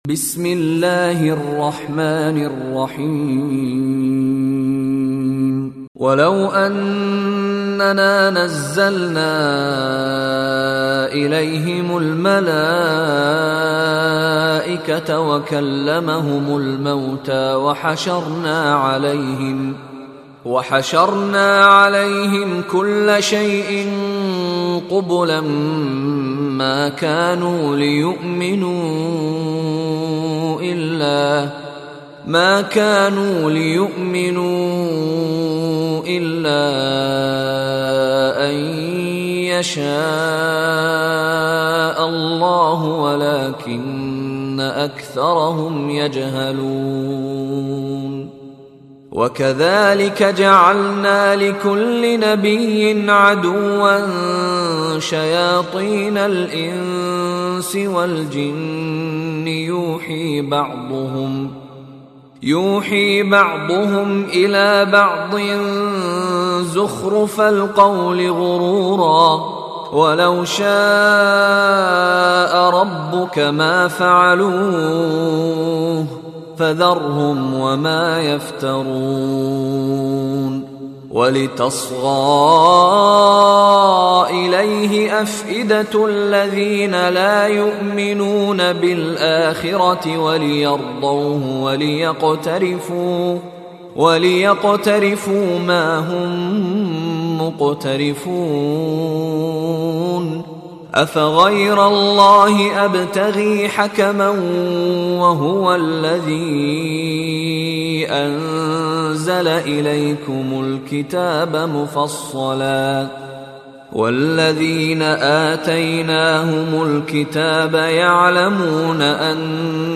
ترتیل جزء 8 هشتم قرآن کریم - مشاری بن راشد العفاسی
دانلود ترتیل جزء هشتم قرآن کریم با صدای مشاری بن راشد العفاسی | مدت : 63 دقیقه